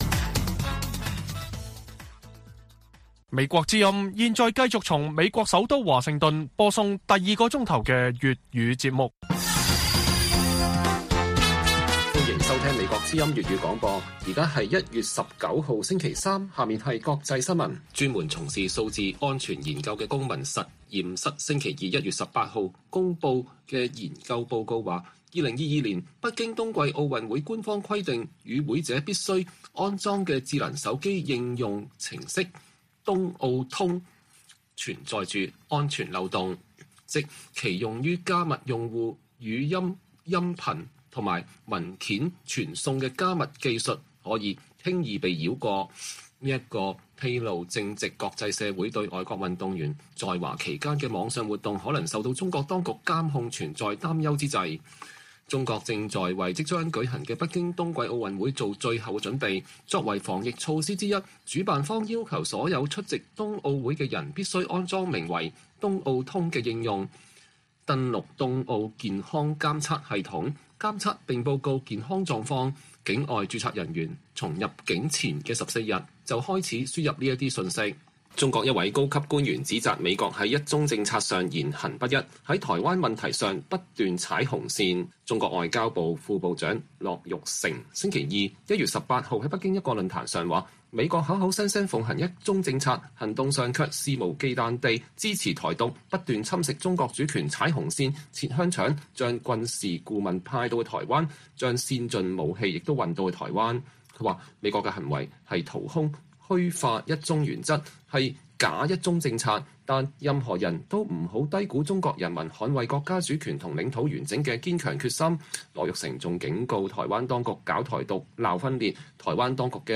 粵語新聞 晚上10-11點: 美國電訊商同意推遲在機場附近部署新的5G移動技術